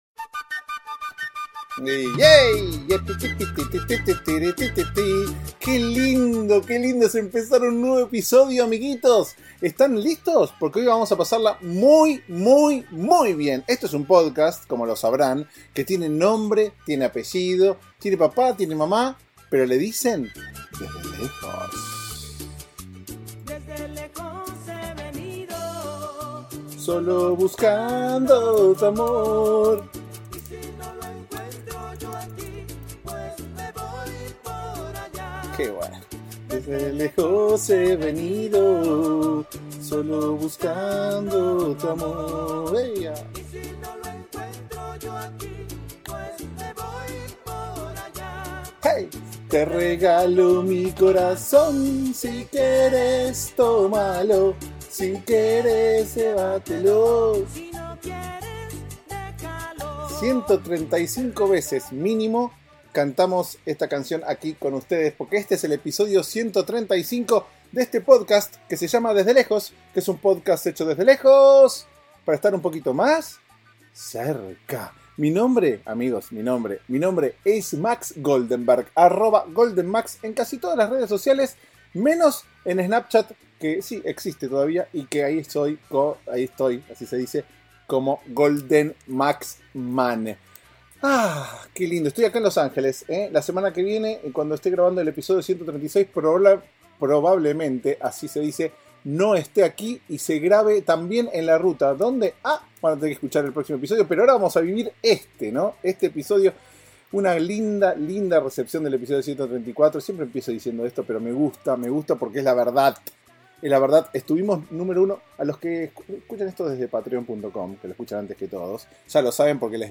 grabamos este episodio desde las entrañas mismas de Los Angeles.